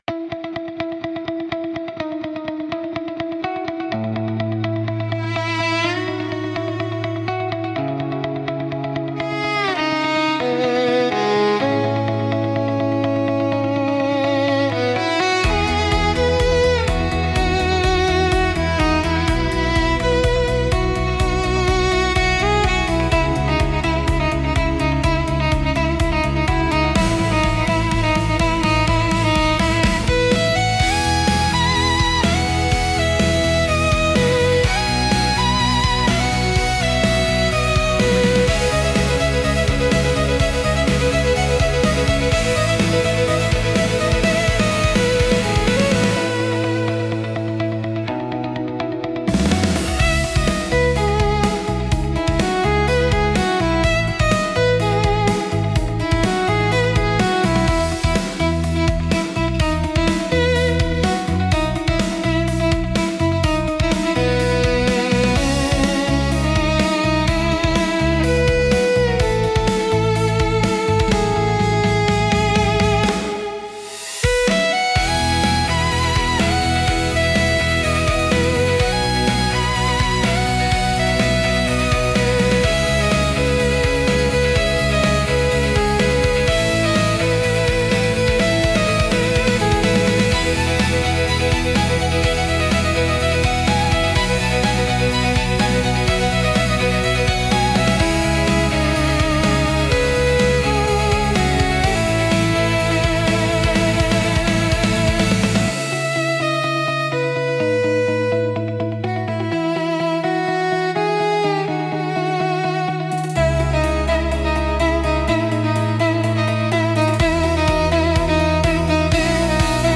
コーポレートは、ミュートギターを主体にした穏やかで落ち着いた曲調が特徴です。
シンプルで洗練されたアレンジが安心感を与え、クリーンでプロフェッショナルな印象を作り出します。